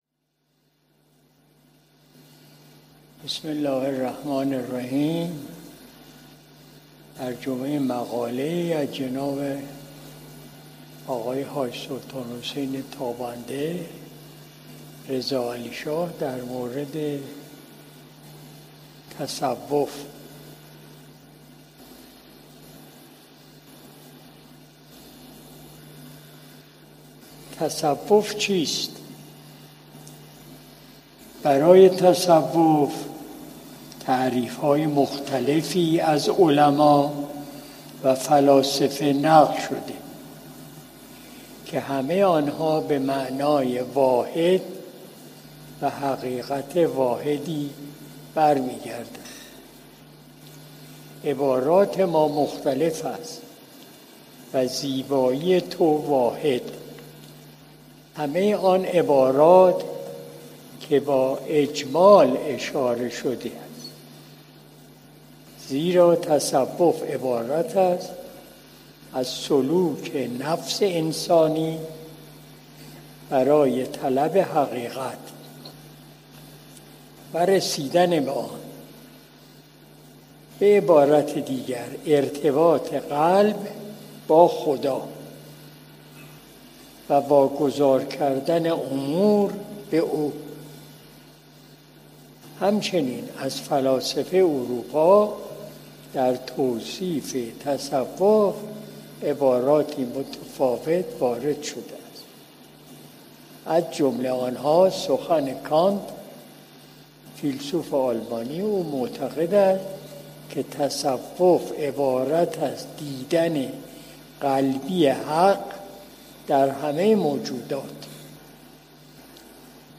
مجلس شب‌دوشنبه ۱۷ اردیبهشت ماه ۱۴۰۲ شمسی